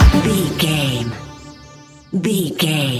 Ionian/Major
D♭
house
electro dance
synths
techno
trance
instrumentals